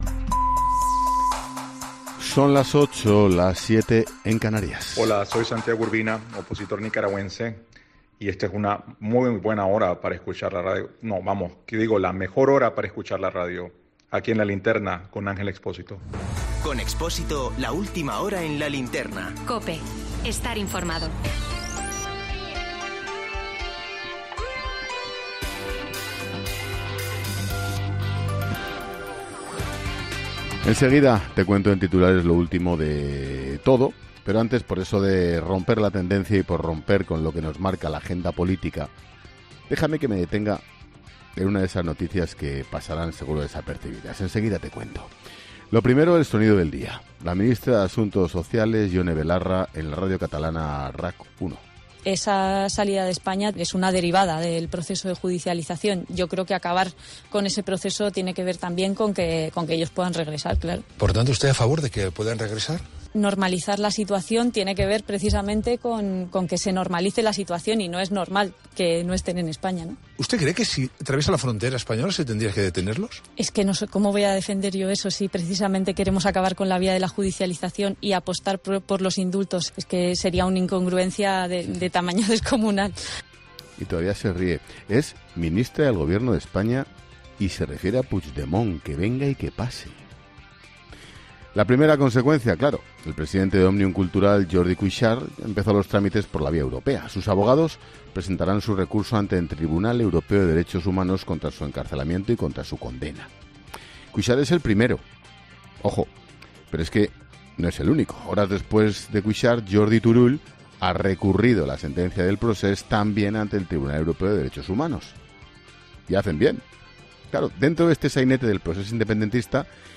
Monólogo de Expósito.